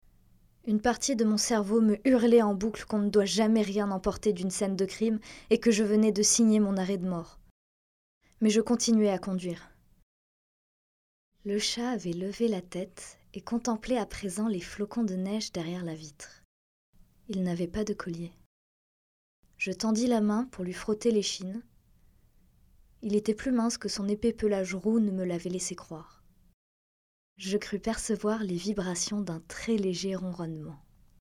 Extrait "Huit Crimes Parfaits" de Peter Swanson (demo)
Comédienne
Voix off
16 - 22 ans - Mezzo-soprano